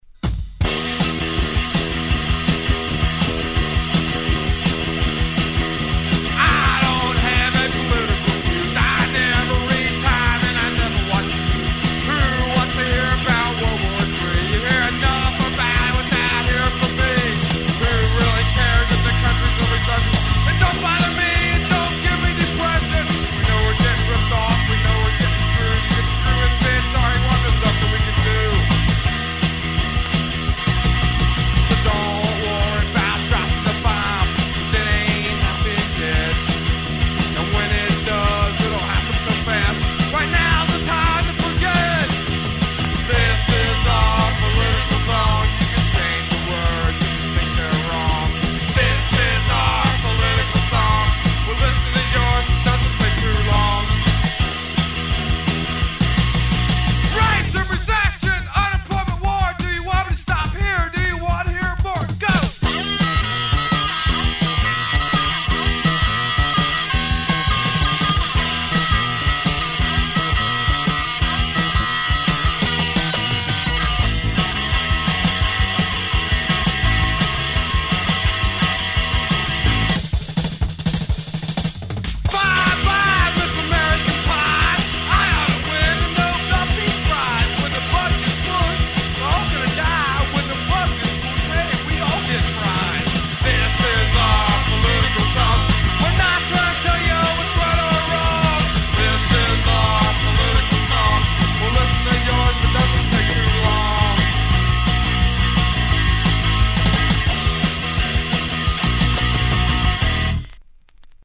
Garage/Roots/Slop-Rock bands